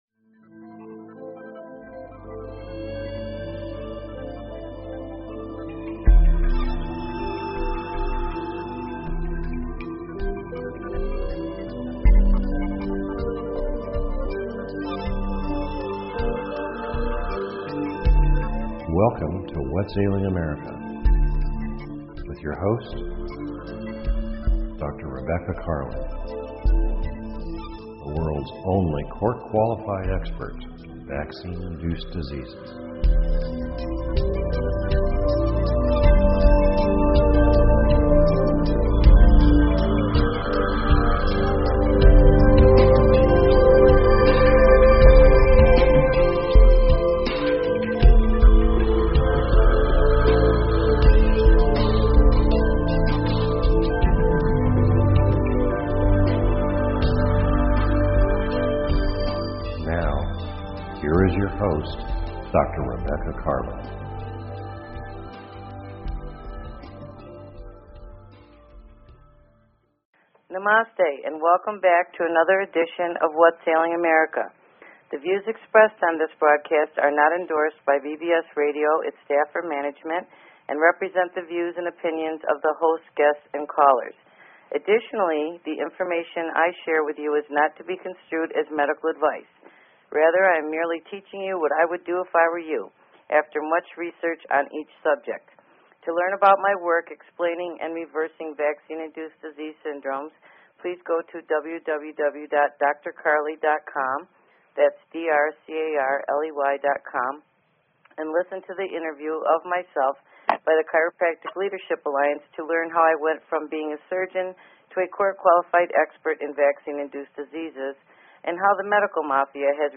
Talk Show Episode, Audio Podcast, Whats_Ailing_America and Courtesy of BBS Radio on , show guests , about , categorized as